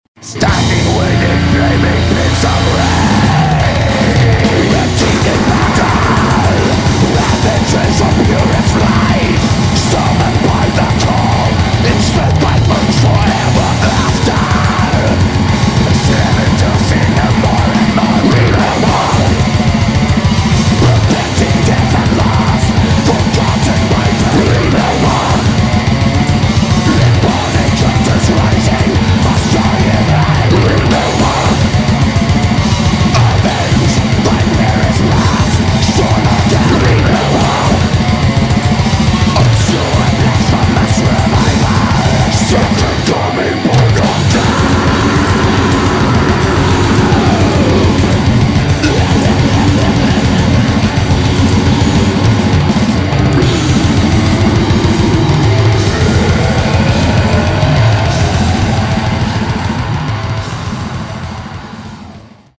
Australian relentless Black Death Metal